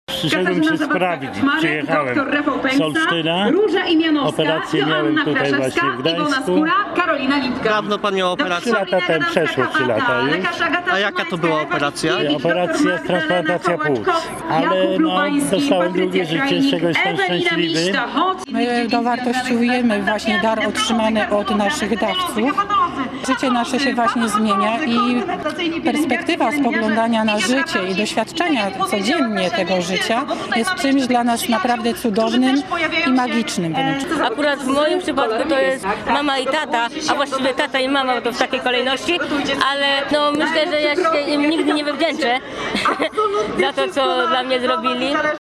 Posłuchaj rozmów naszego reportera z uczestnikami biegu: